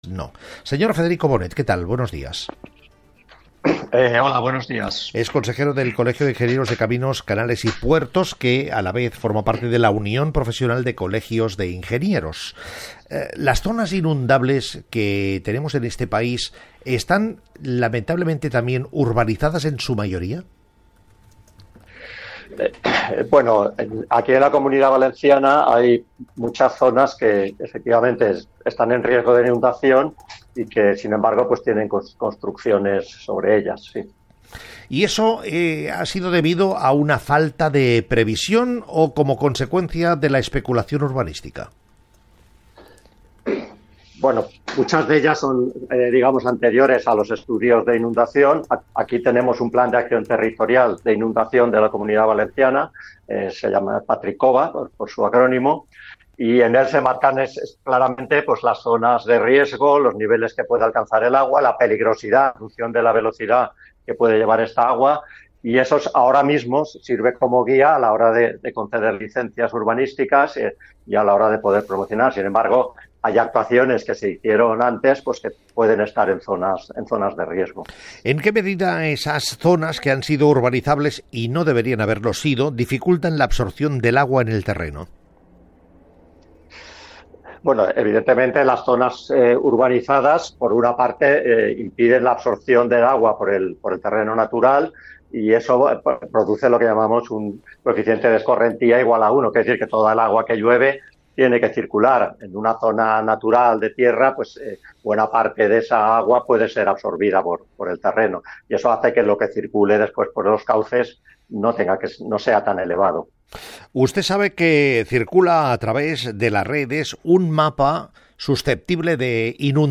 Josep Cuní habla en Radio Nacional de España